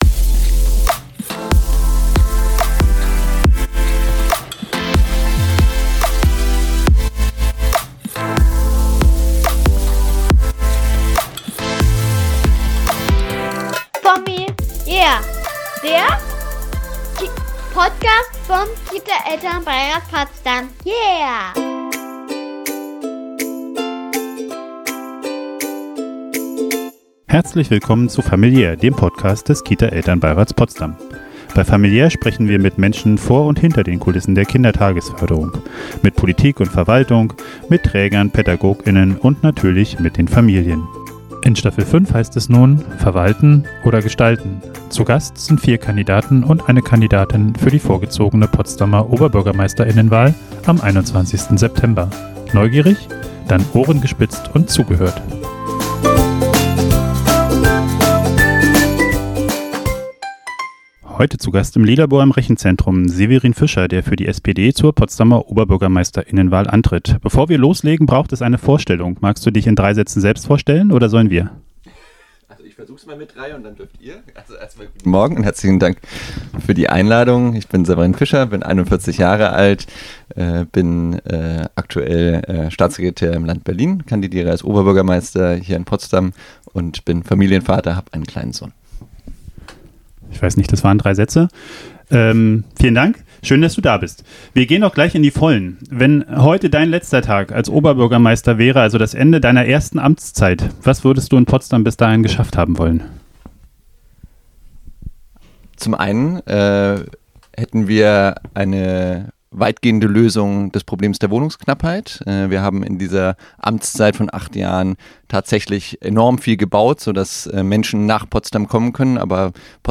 Zur fünften Staffel begrüßen wir vier Kandidaten und eine Kandidatin zur vorgezogenen Potsdamer Oberbürgermeister*innenwahl im Lilabor im Rechenzentrum.
Freut euch auf über zwei Stunden Austausch – natürlich über Familien- und Kita-Themen, aber auch darüber hinaus, aufgeteilt in fünf badewannentaugliche Interviews.